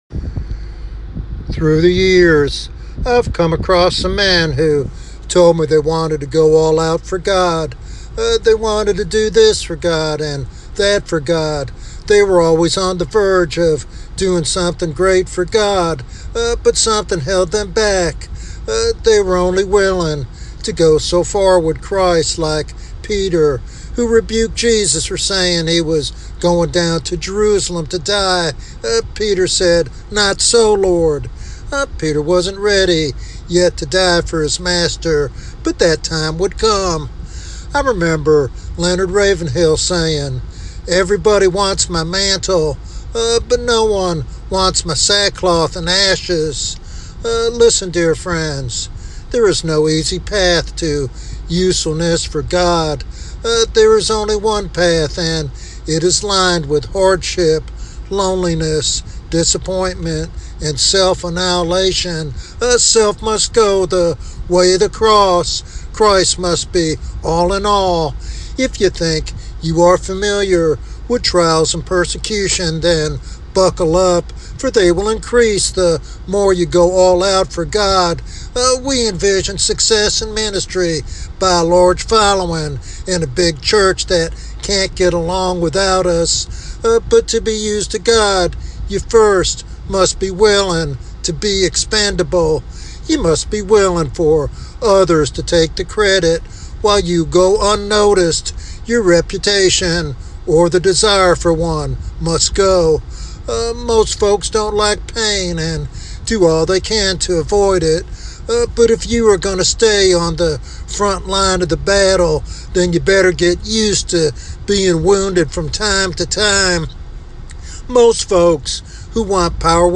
This sermon encourages listeners to persevere through trials, trusting that God is purifying them to be vessels fit for His service.